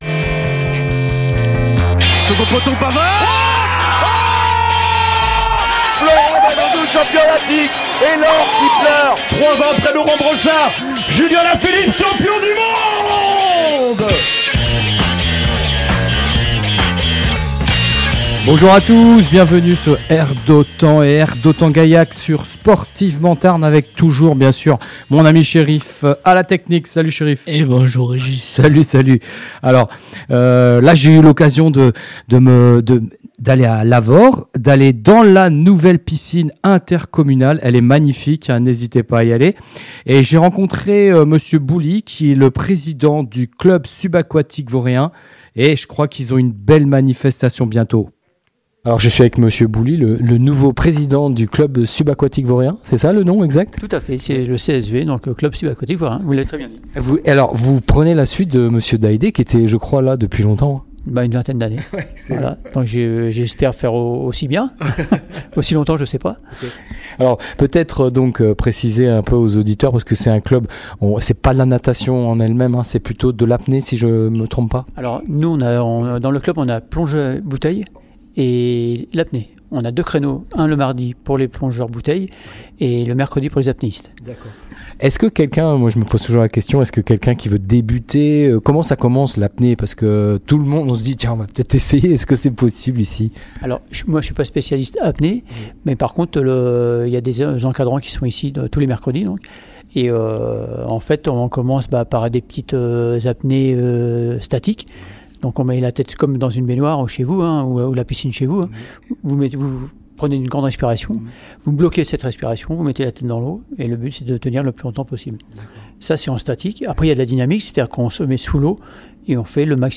Le CSV diffusé sur la radio R d'autan